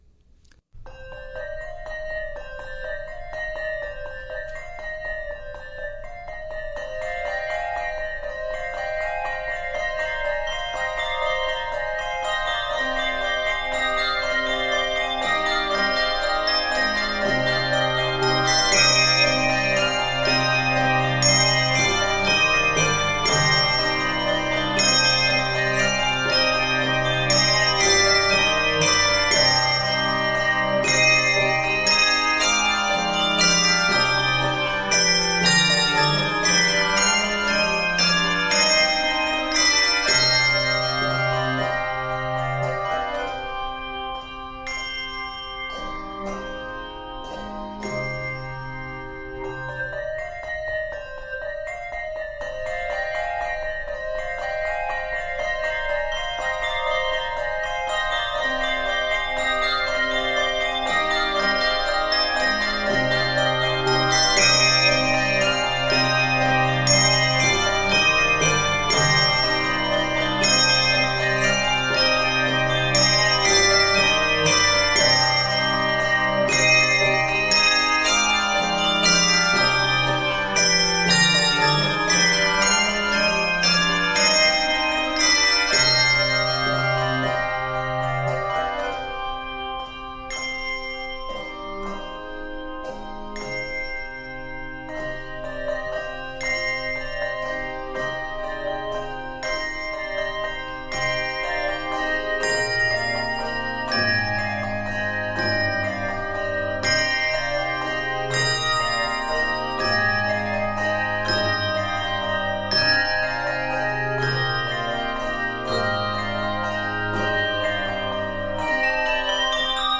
Key of c minor.
Octaves: 3-6